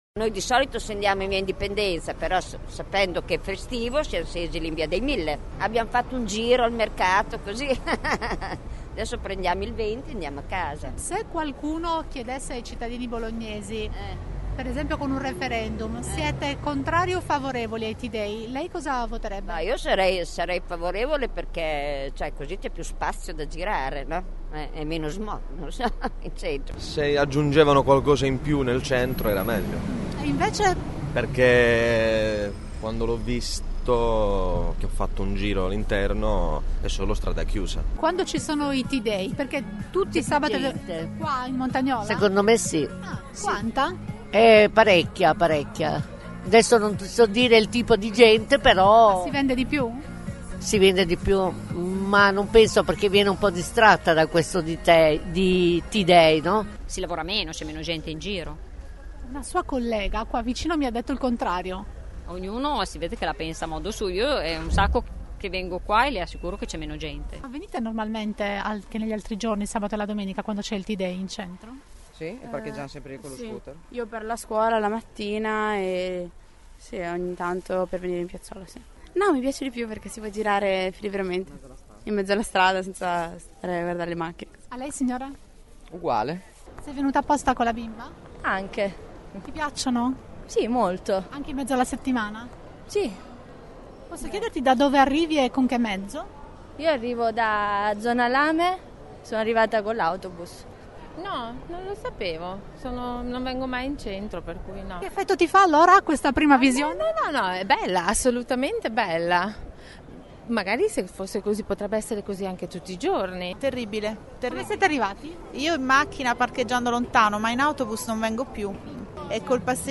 Nel primo t-day infrasettimanale abbiamo fatto un giro mattutino lungo le strade della T. Difficile trovare persone impreparate sulle modifiche alla viabilità o pareri completamente contrari alla chiusura delle strade ai mezzi a motore
voci varie sito